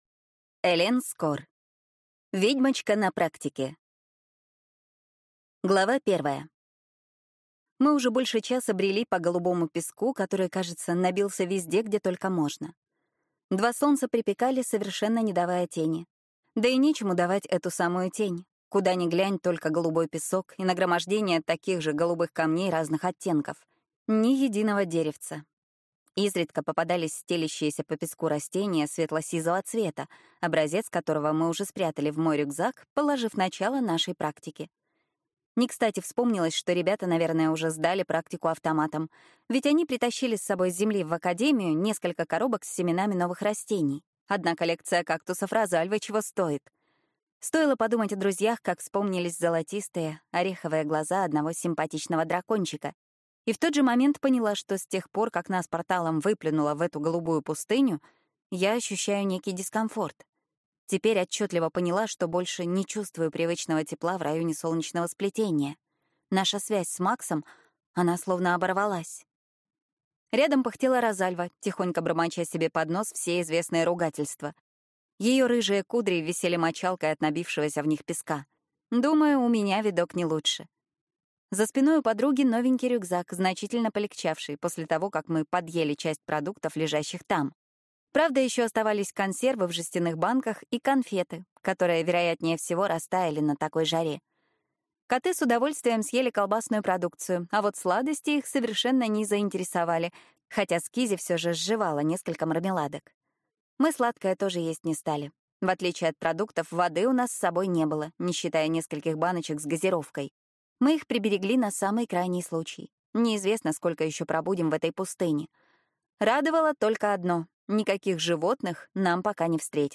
Аудиокнига Ведьмочка на практике | Библиотека аудиокниг